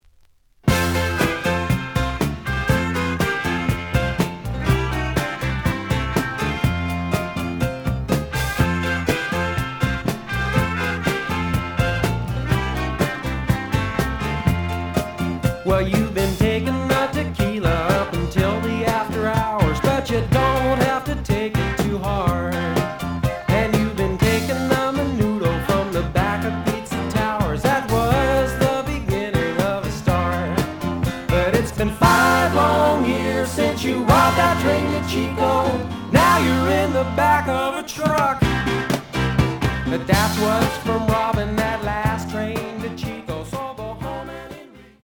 The audio sample is recorded from the actual item.
●Genre: Rock / Pop
Slight edge warp. But doesn't affect playing. Plays good.